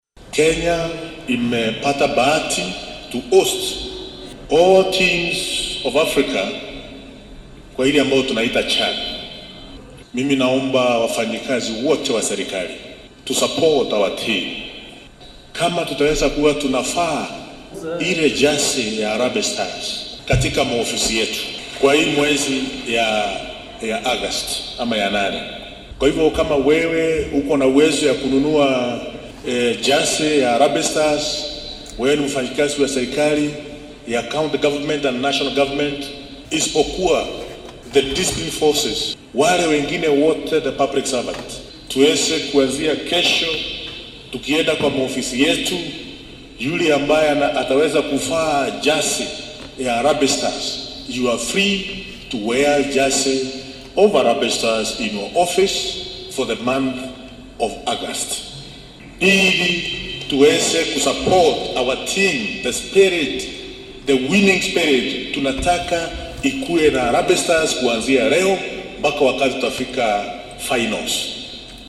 Wasiir Ruku oo baaqan diray xilli uu munaasabad kaniiseed uga qayb galay deegaanka Waqooyiga Mbeere ee ismaamulka Embu ayaa yidhi.